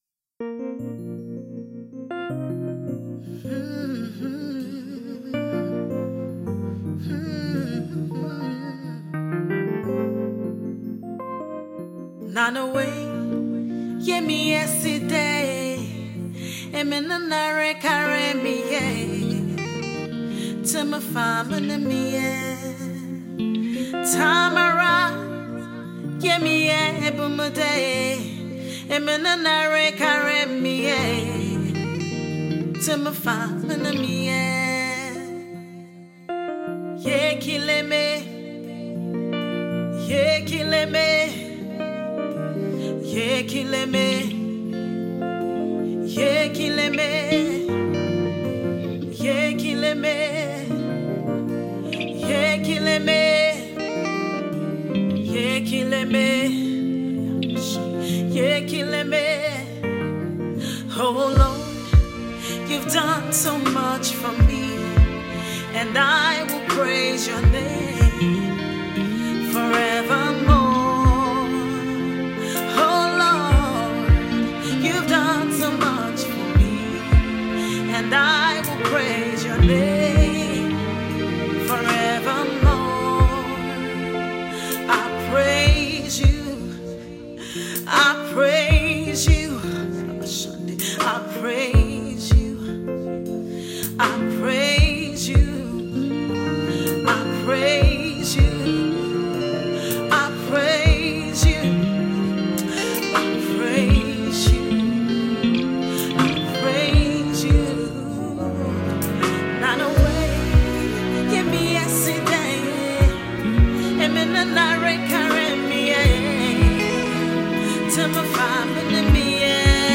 Gospel Worship song
flow easily with the emotive production